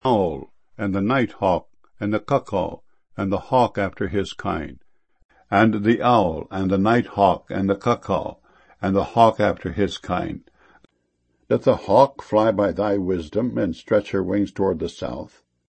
hawk.mp3